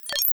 button.ogg